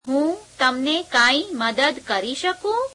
[heum temene kemī meded kereī śhekeum?]